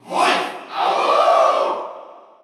Category: Crowd cheers (SSBU) You cannot overwrite this file.
Wolf_Cheer_Russian_SSBU.ogg